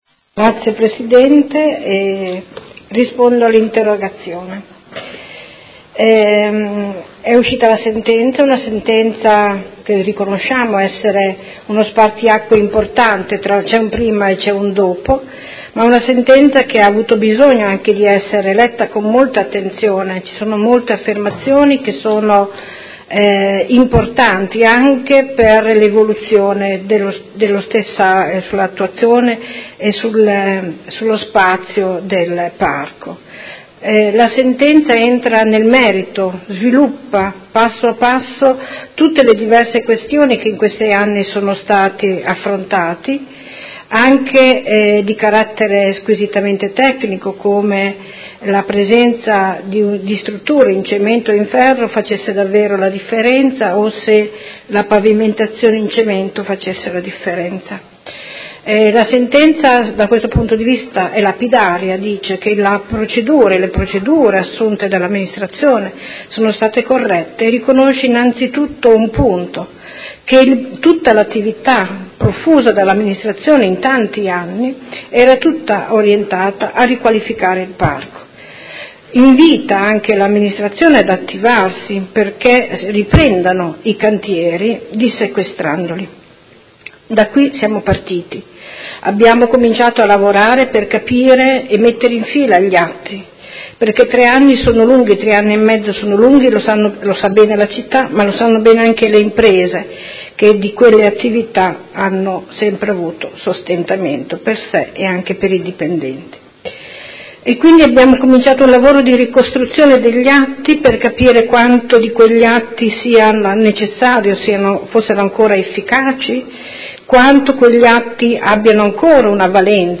Seduta dell'11/01/2018 Risponde. Interrogazione del Consigliere Morandi (FI) avente per oggetto: Dopo la sentenza del Tribunale di Modena che ha assolto i tecnici comunali e liberato dal sequestro i chioschi del Parco delle Rimembranze, questi potranno essere aperti e fornire il servizio utile che tutti i cittadini chiedono?